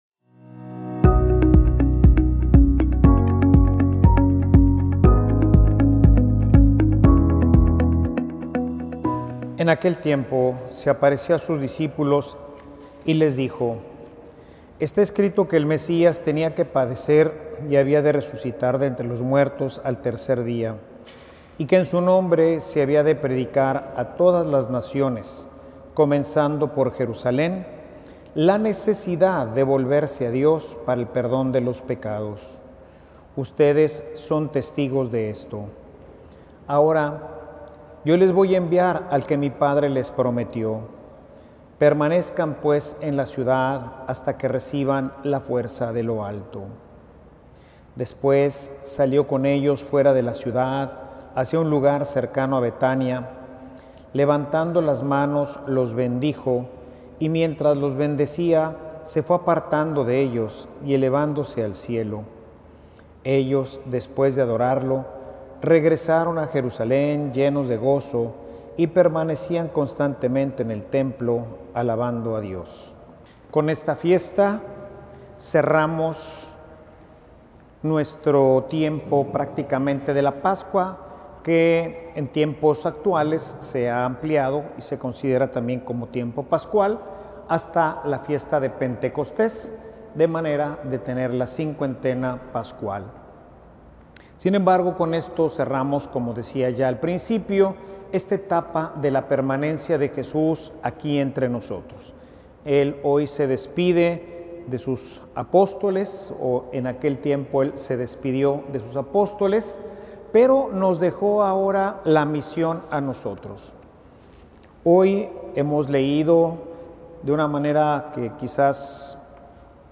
Homilia_Somos_continuadores_de_Cristo.mp3